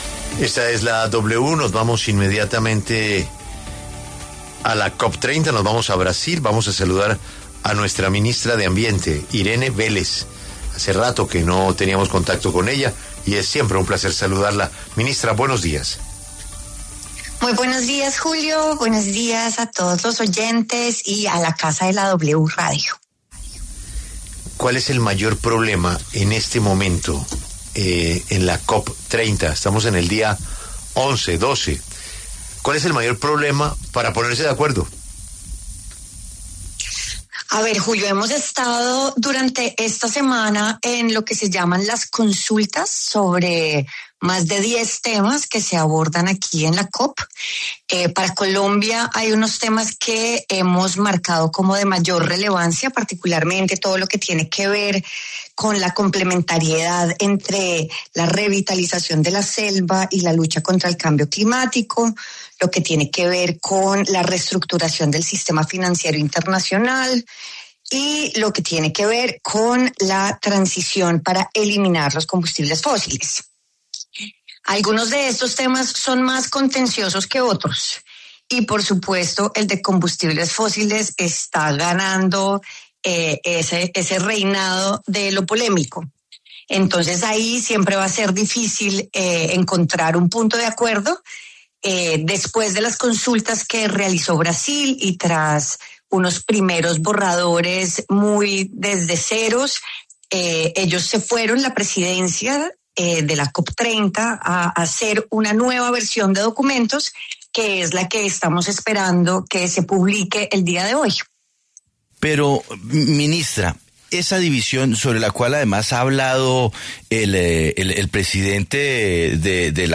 Este jueves, 20 de noviembre, la ministra de Ambiente, Irene Vélez, habló en La W, con Julio Sánchez Cristo, en el marco de la COP30 que se lleva a cabo en Brasil.